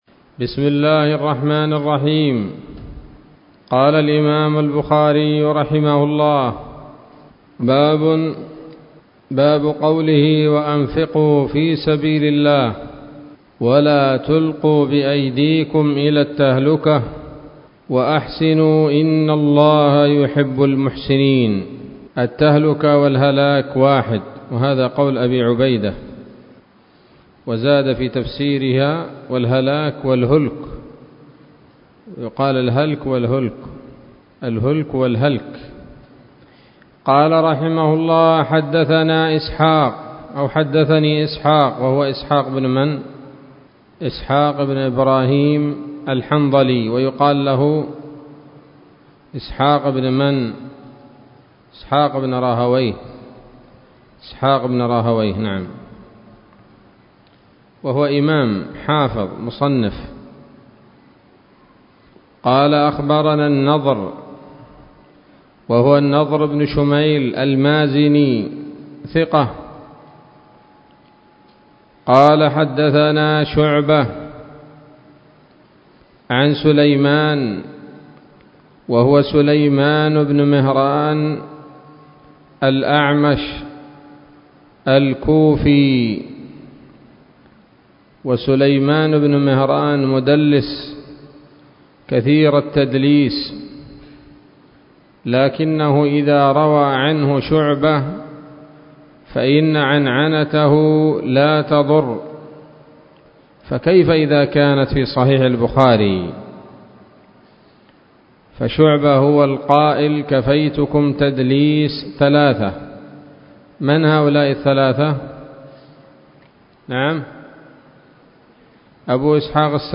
الدرس السادس والعشرون من كتاب التفسير من صحيح الإمام البخاري